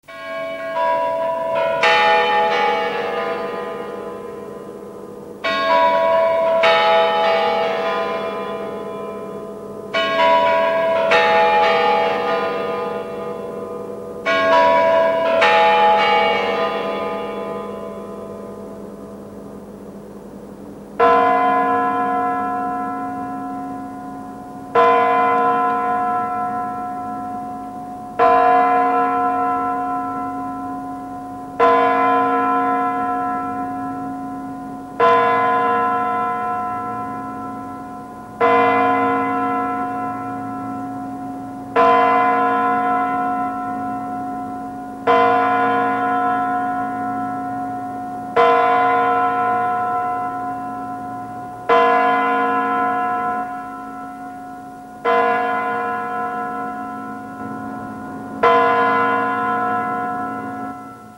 Boy_kur.mp3  (размер файла: 958 Кб, MIME-тип: audio/mpeg ) бой Курантов История файла Нажмите на дату/время, чтобы просмотреть, как тогда выглядел файл.